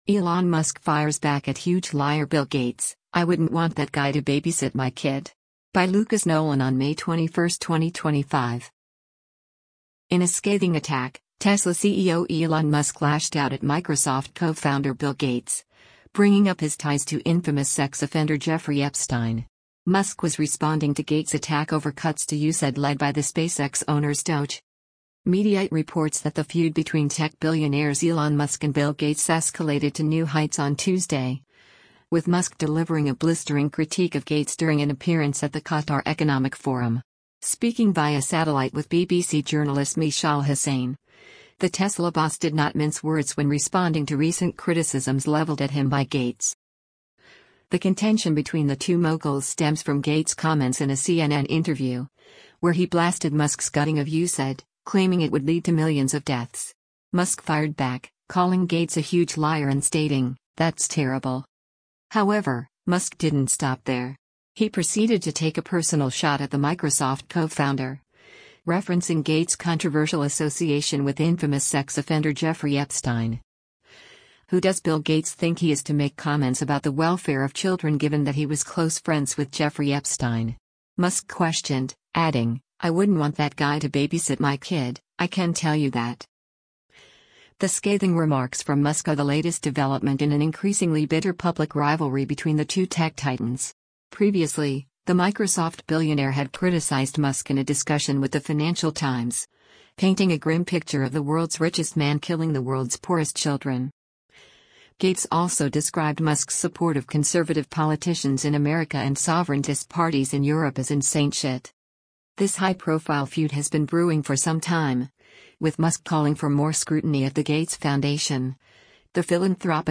Speaking via satellite with BBC journalist Mishal Husain, the Tesla boss did not mince words when responding to recent criticisms leveled at him by Gates.